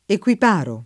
vai all'elenco alfabetico delle voci ingrandisci il carattere 100% rimpicciolisci il carattere stampa invia tramite posta elettronica codividi su Facebook equiparare v.; equiparo [ ek U ip # ro ; alla lat. ek U& paro ]